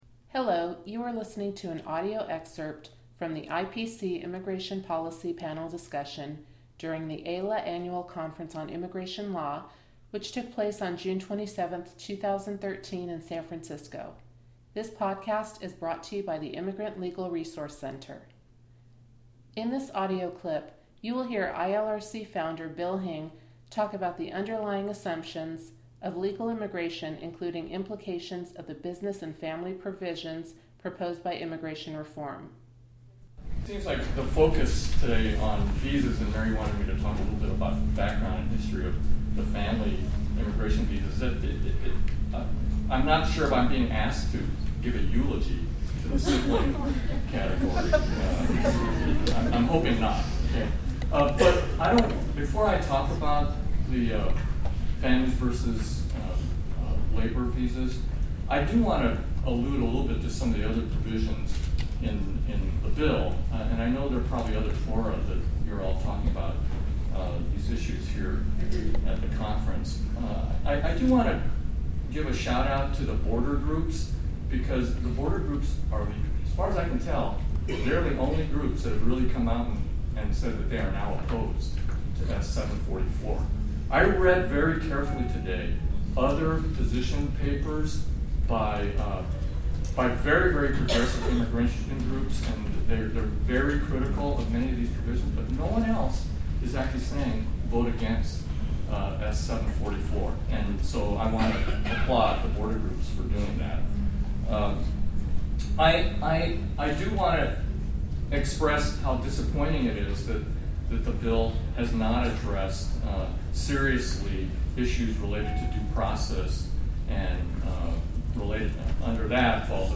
IPC Immigration Policy Panel Discussion - New Podcast | Immigrant Legal Resource Center | ILRC
recorded during the AILA Annual Conference